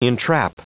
Prononciation du mot entrap en anglais (fichier audio)
Prononciation du mot : entrap